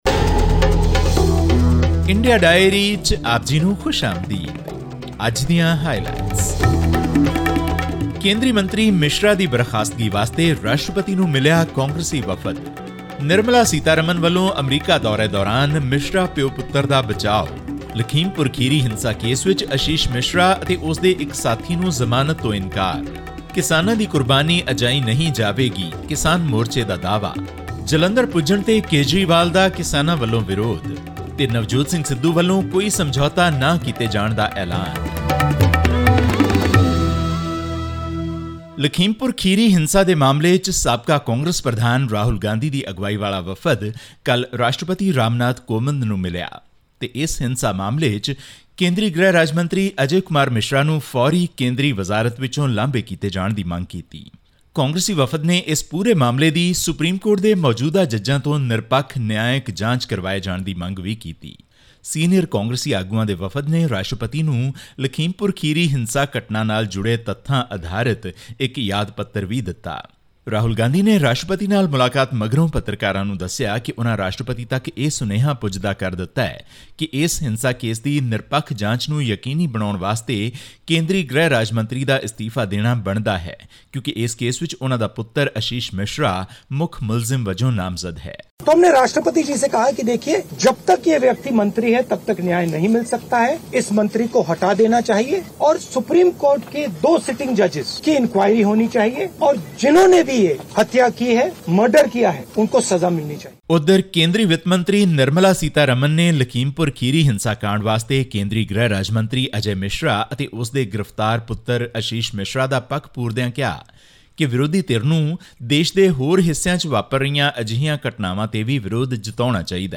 The Indian government's decision to extend the jurisdiction of the Border Security Force (BSF) and enhance its powers to arrest, search, and seizure has triggered a political row in Punjab. All this and more in our weekly news segment from India.